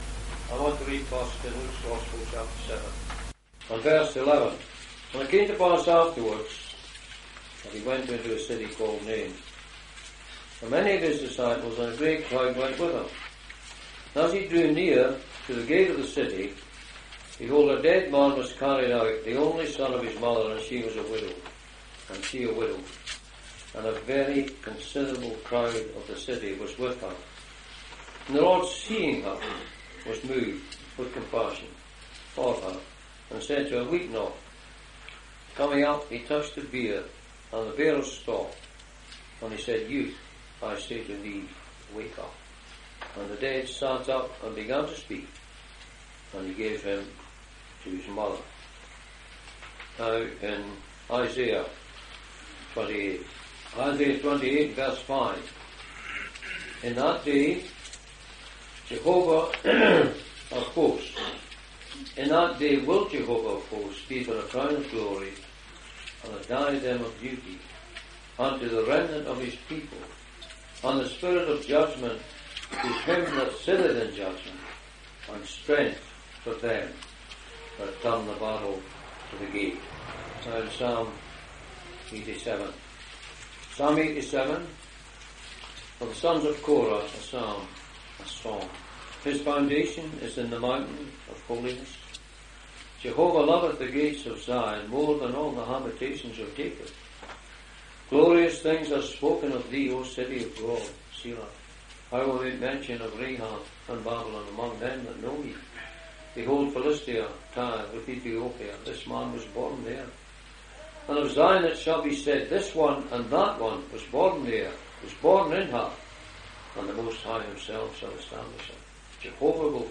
In this Address, you will hear of five scriptures that refer to Gates. The Lord Jesus delights in those who are faithful to Him.